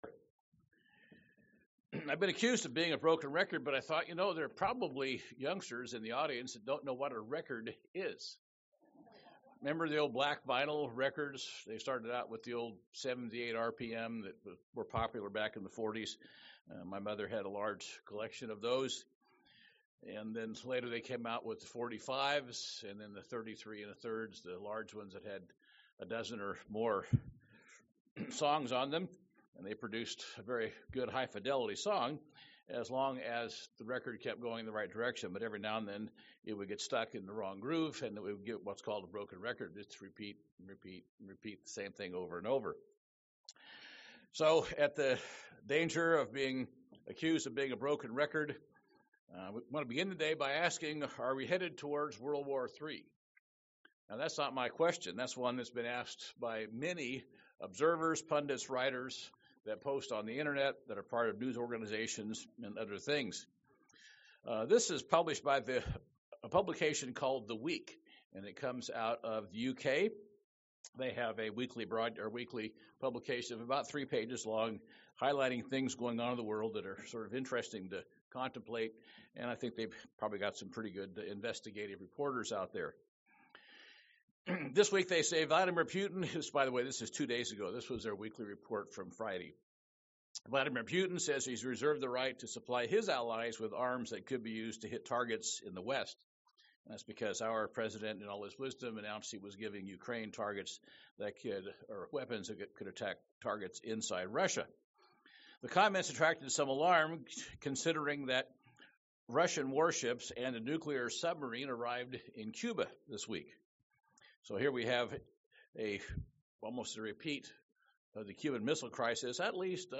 Given in Portland, OR